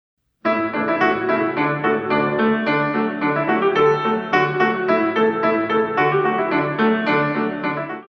In 2
32 Counts